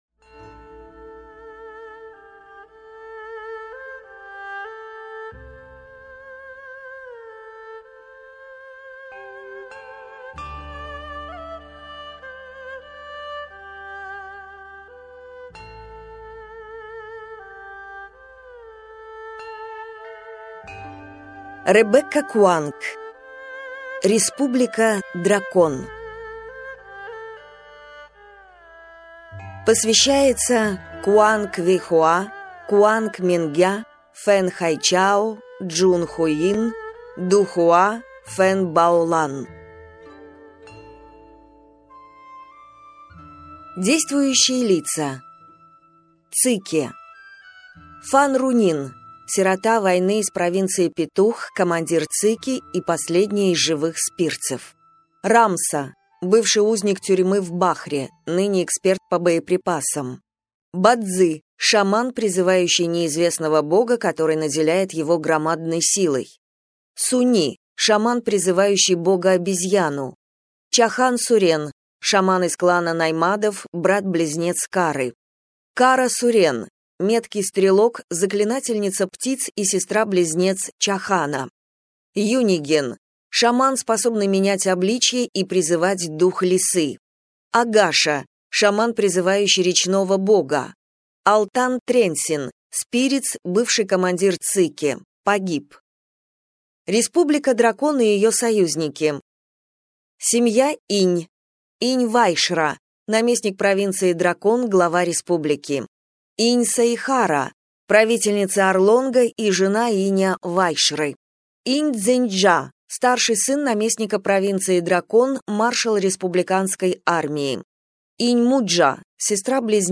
Аудиокнига Республика Дракон | Библиотека аудиокниг